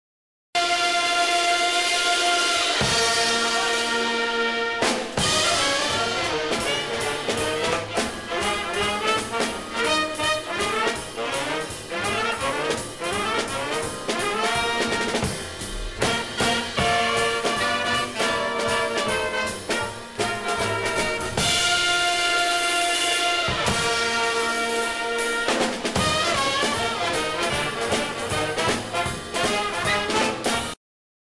The guest musician was Clark Terry on the trumpet.
Jazz Ensemble; Clark Terry (trumpet, vocal)
alto saxophone
tenor saxophone
baritone saxophone
trombone
piano
vibes
percussion
bass
Jazz vocals